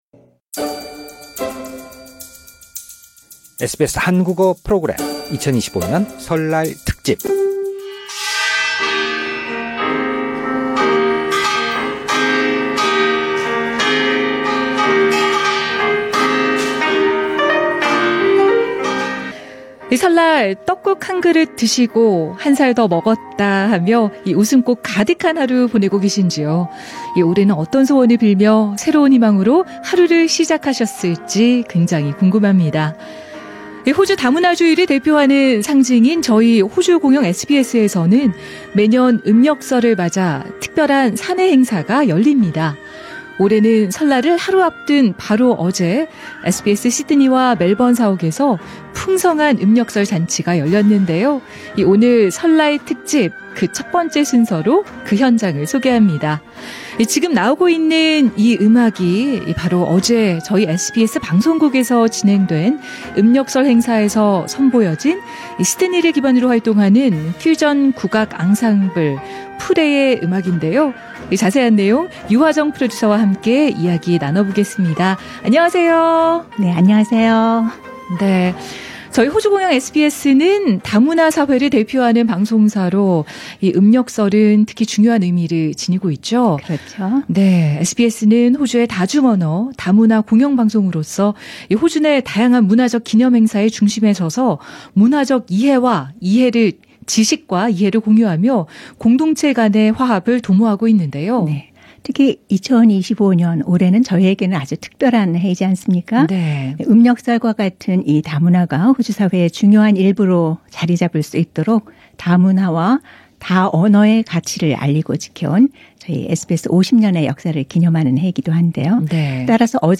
지금 나오고 있는 이 음악이 바로 어제 저희 SBS 방송국에서 진행된 음력설 행사에서 선보여진 시드니를 기반으로 활동하는 퓨전 국악 앙상블 '푸레'의 음악인데요.